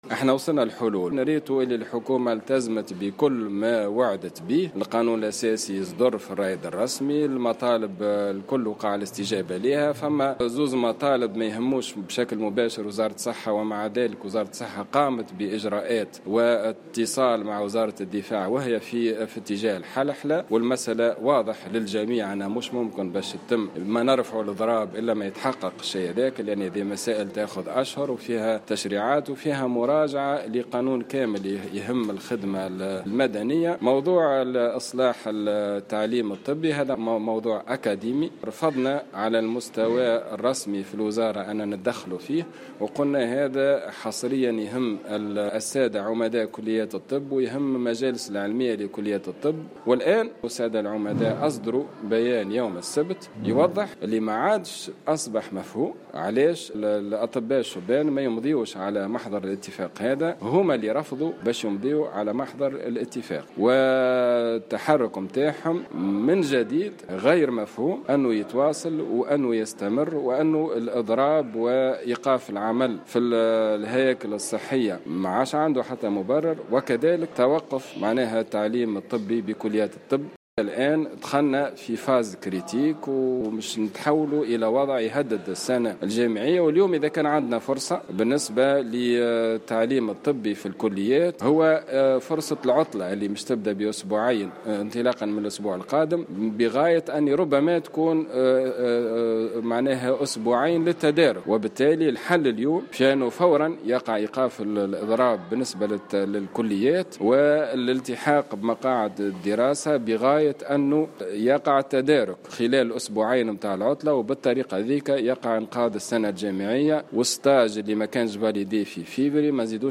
أكد وزير الصحة عماد الحمامي في تصريح لمراسل الجوهرة "اف ام" اليوم الإثنين 12 مارس 2018 أن أزمة اضراب الأطباء الشبان متواصلة خصوصا بعد رفضهم الإمضاء على محضر الاتفاق رغم استجابة الوزارة والحكومة لجل مطالبهم.